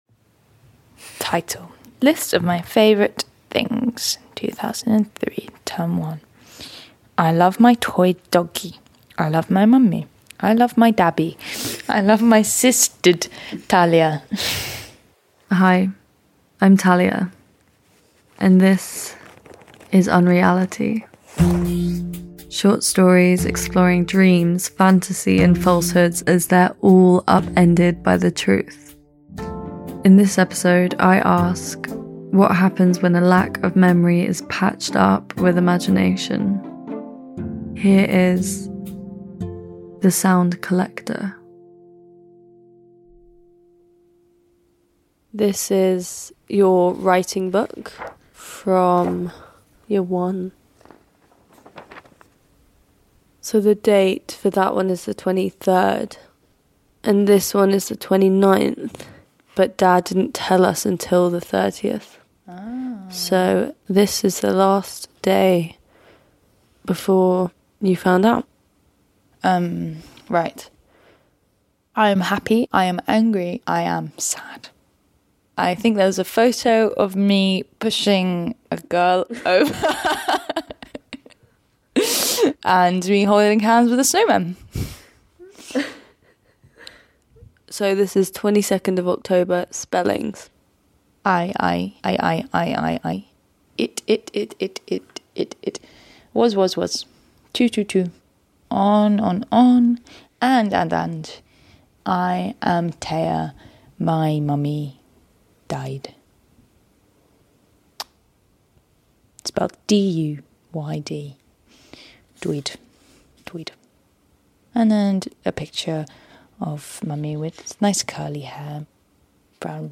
Two sisters rifle through an old exercise book. And in discovering forgotten memories, they create some new ones.